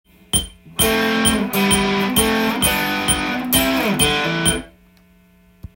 付点４分音符になっています。この音符は、通常の４分音符の１，５倍の長さになるので
口でリズムを言ってみると「タタタ、タタタ、タタ」です。
ギターで弾いてみました。